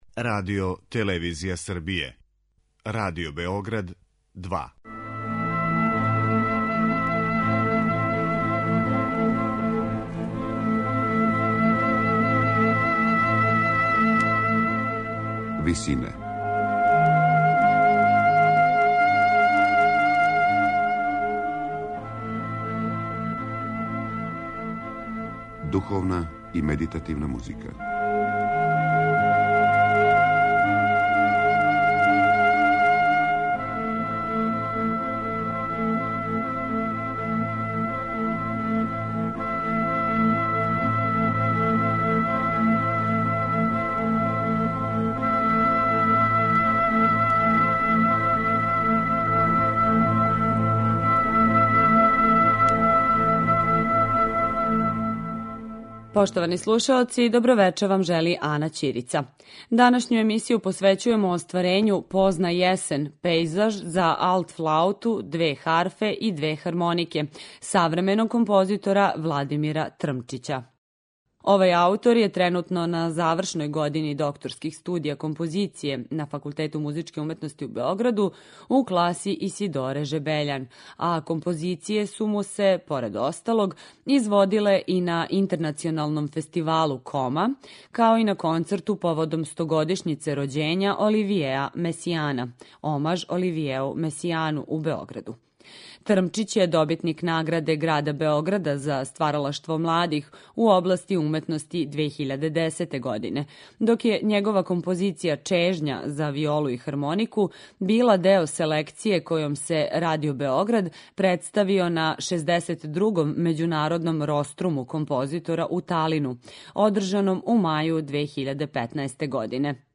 Емисија медитативних и духовних композиција